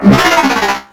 CosmicRageSounds / ogg / general / combat / ENEMY / droid / att2.ogg